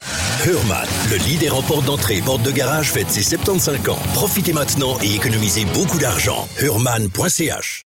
Sprecher mit breitem Einsatzspektrum.